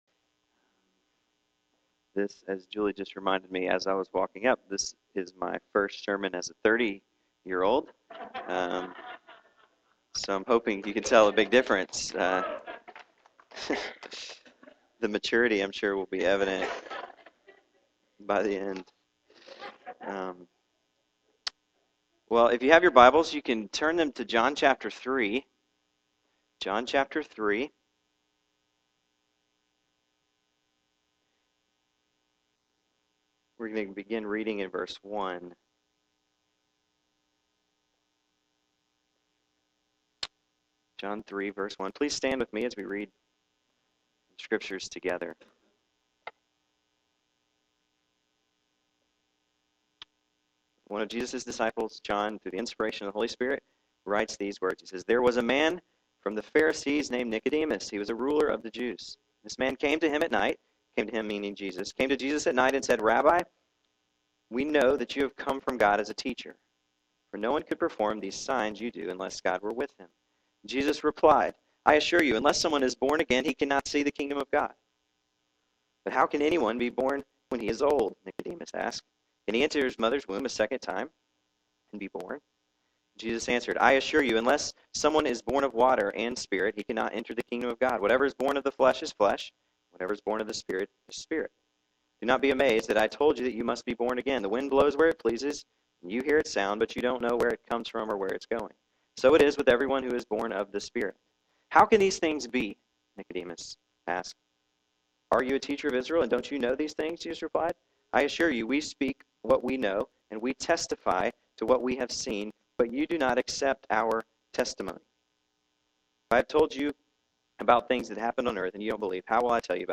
Sermons for January 2011